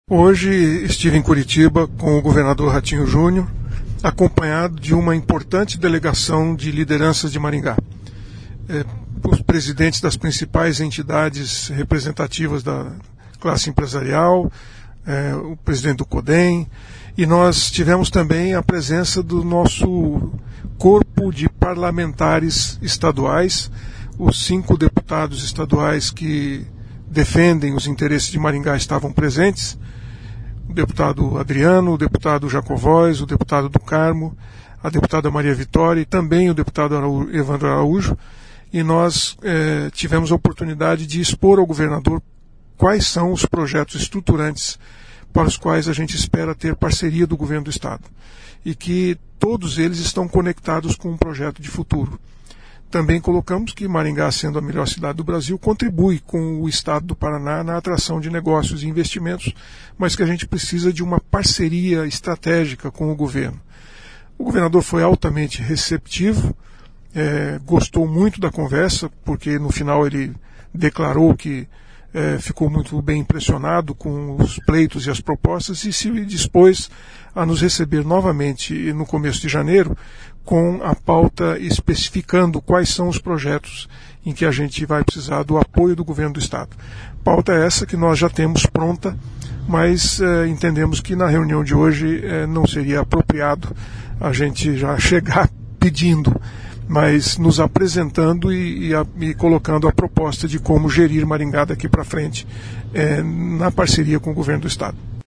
Nesta terça-feira (10), o prefeito eleito Silvio Barros esteve em Curitiba acompanhado de outras lideranças, como deputados estaduais, para apresentar projetos estruturantes que serão executados na próxima gestão, mas dependem de apoio do Governo do Estado. Ouça o que diz o prefeito eleito Sílvio Barros: